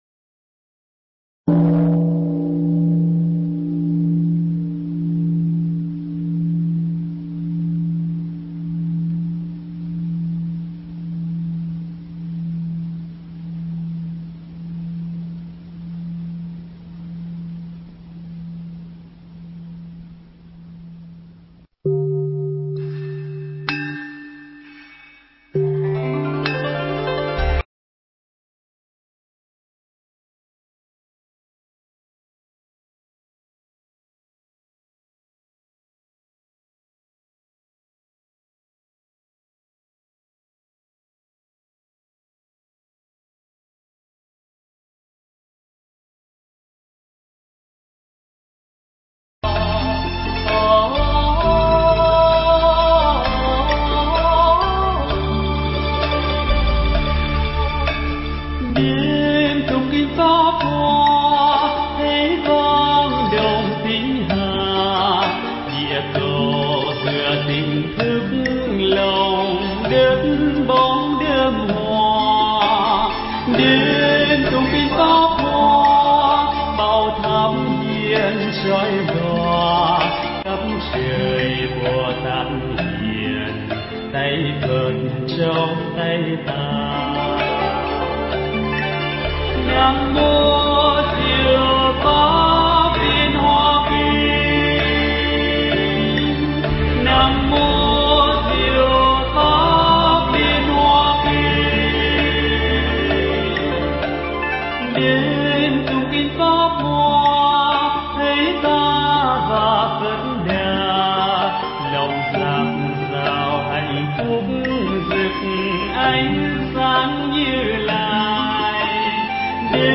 Nghe Mp3 thuyết pháp Diệu Pháp Liên Hoa Kinh Phẩm Tùng Địa Dõng Xuất